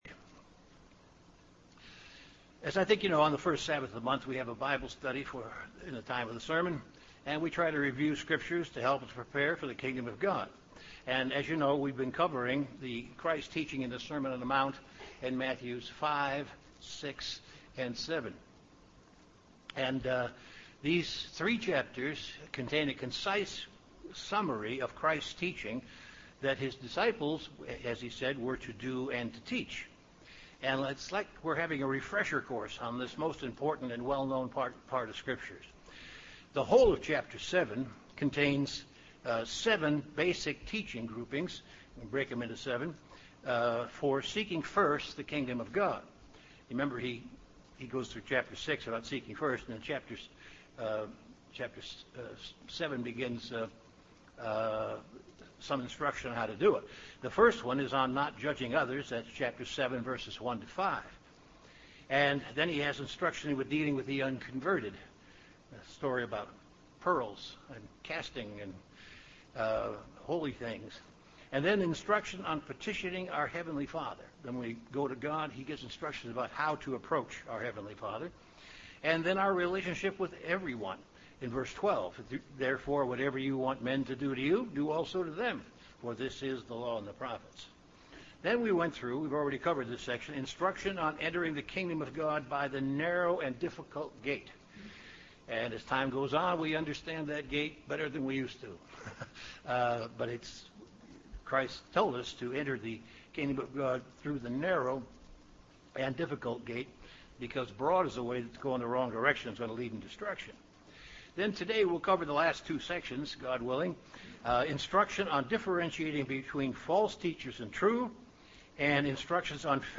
Given in Chicago, IL
UCG Sermon Sermon on the Mount Studying the bible?